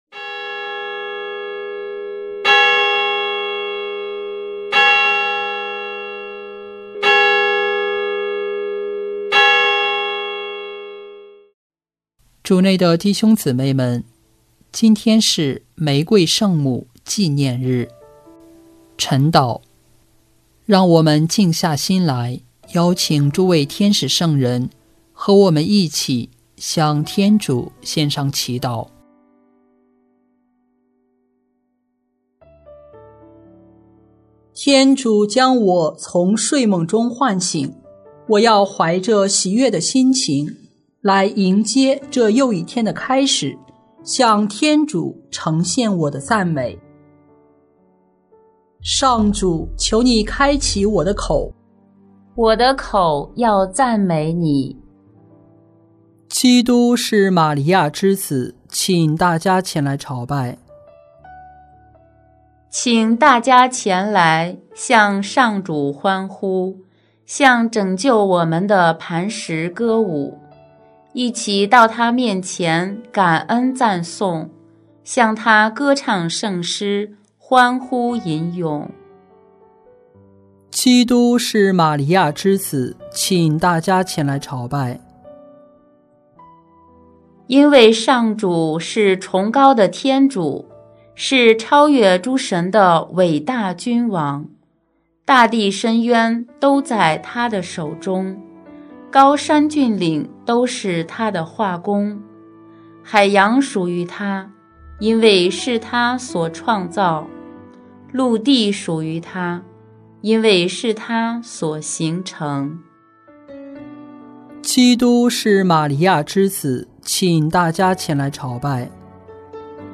【每日礼赞】|10月7日玫瑰圣母纪念日晨祷